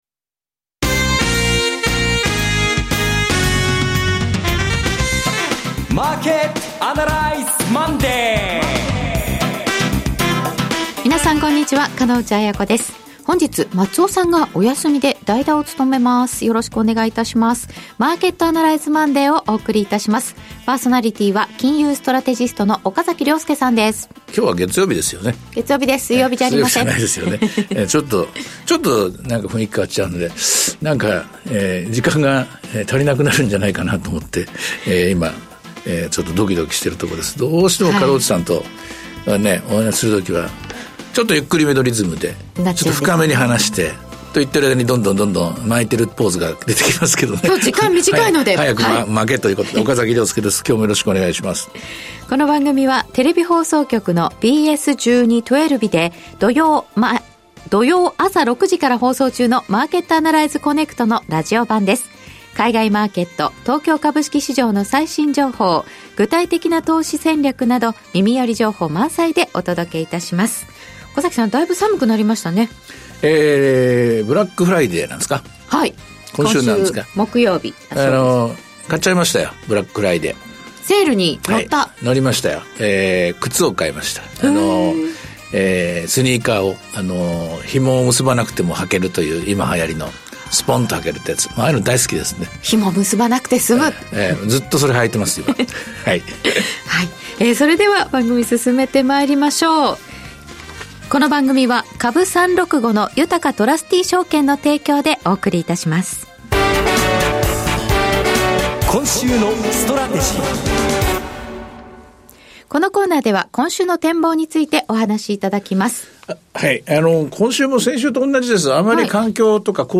ＢＳ１２ ＴwellＶの「マーケット・アナライズ コネクト」のラジオ版。今週のマーケットはどうなるか？投資家はどう対応すればよいのか、等を２５分間に凝縮してお届けします。